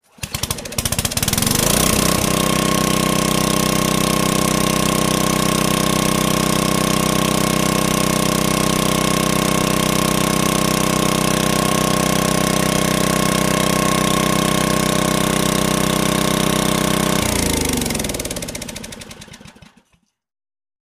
Electric generator starts and shuts off. Electric Generator Motor, Generator Engine, Generator